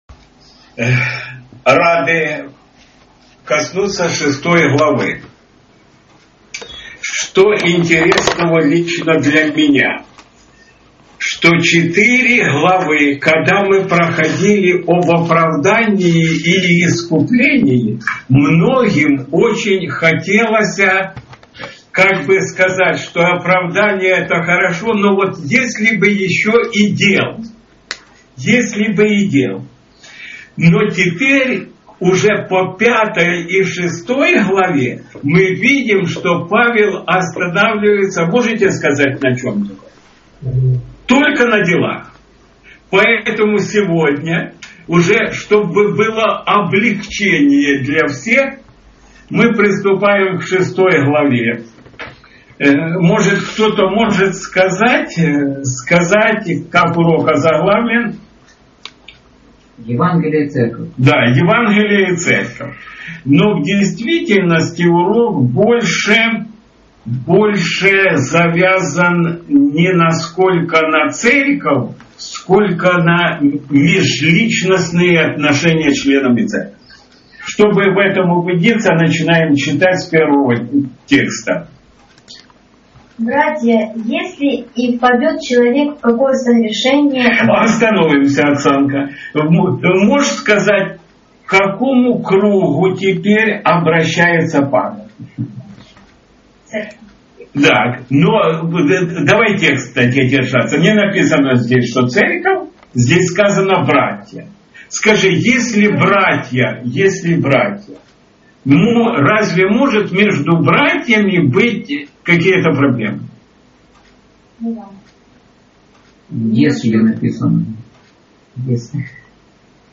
Евангелие и Церковь. 13 - Субботняя школа